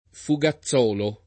DOP: Dizionario di Ortografia e Pronunzia della lingua italiana
[ fu g a ZZ0 lo ]